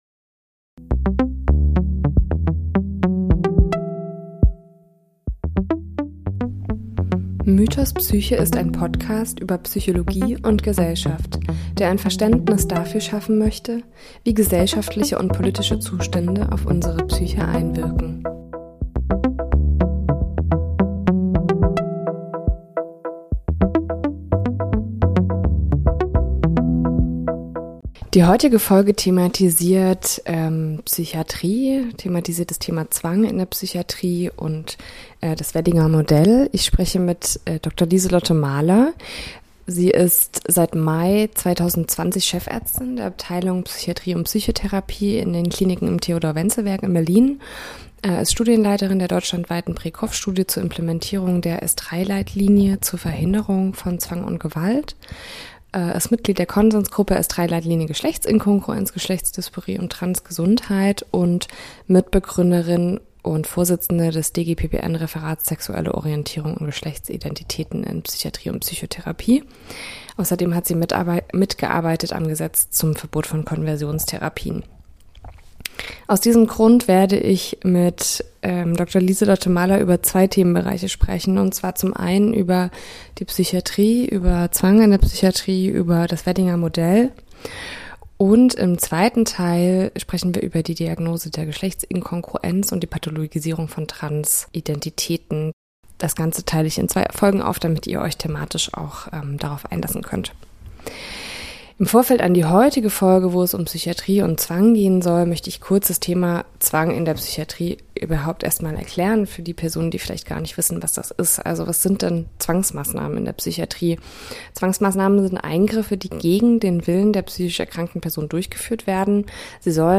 Podcast-Studio Berlin